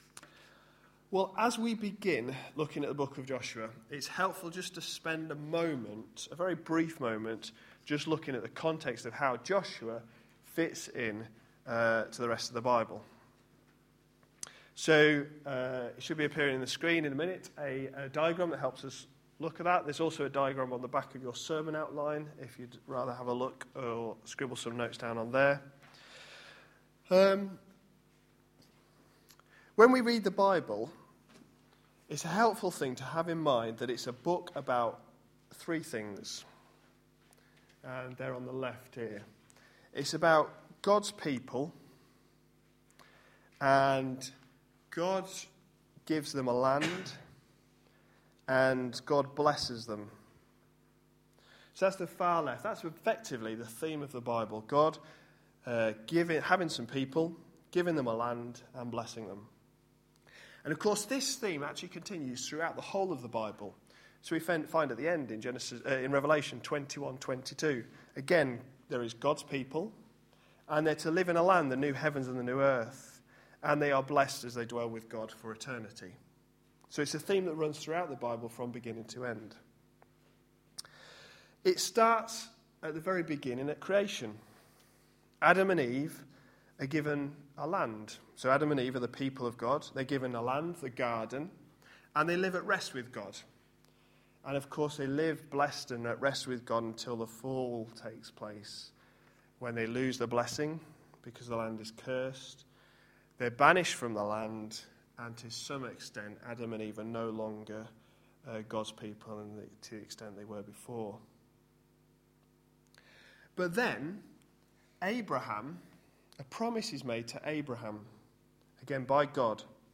A sermon preached on 15th April, 2012, as part of our Entering God's Rest series.